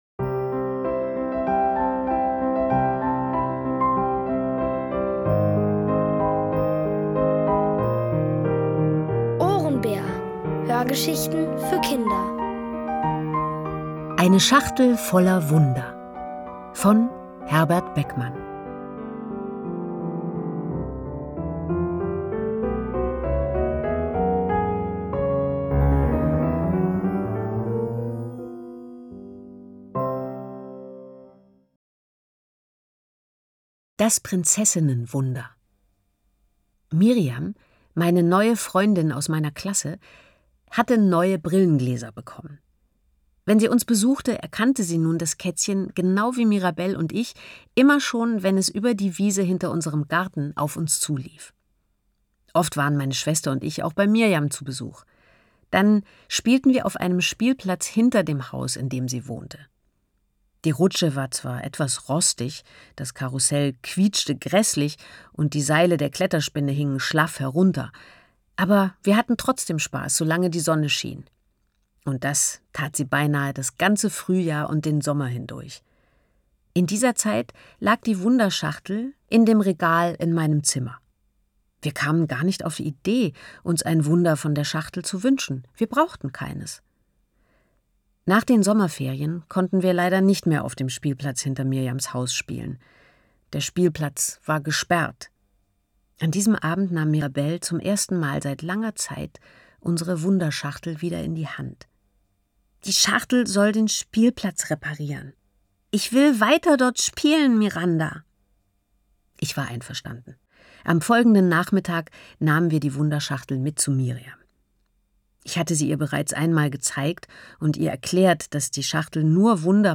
Von Autoren extra für die Reihe geschrieben und von bekannten Schauspielern gelesen.
liest: Nina Hoss.